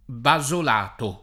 [ ba @ ol # to ]